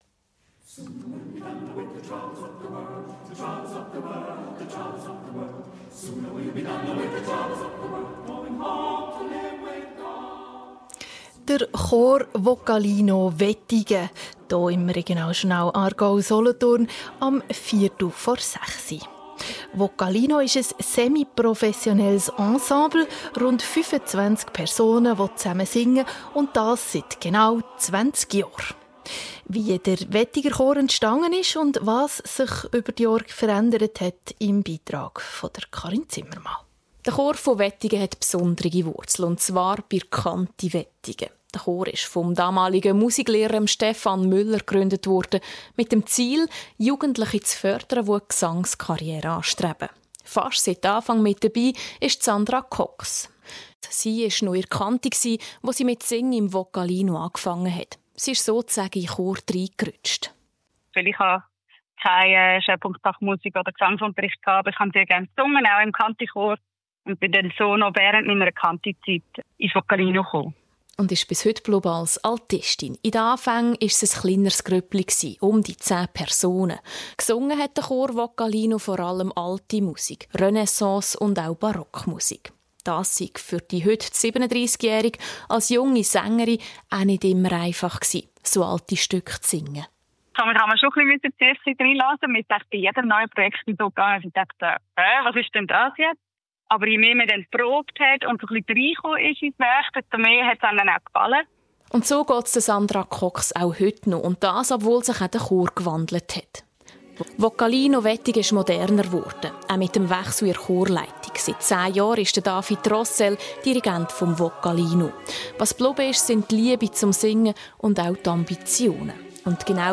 Es handelt sich um einen Ausschnitt der Sendung Regionaljournal Aargau Solothurn vom 12. September 2025.